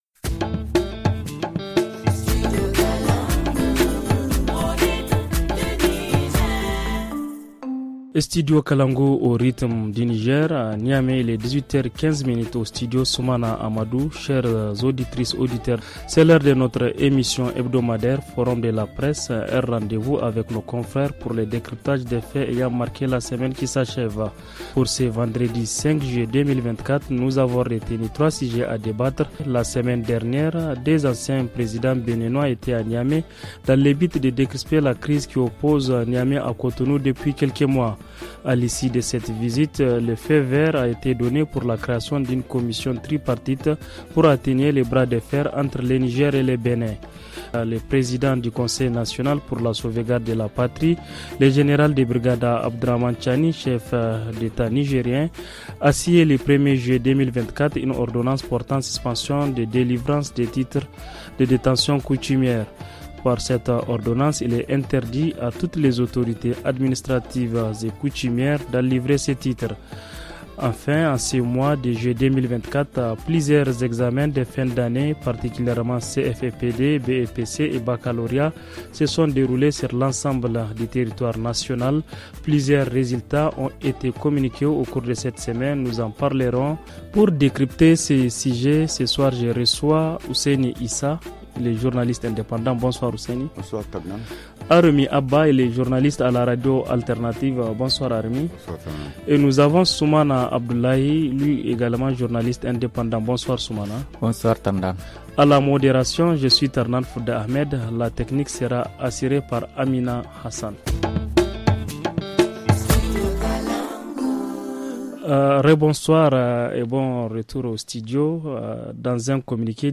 Dans le forum de la presse de cette semaine :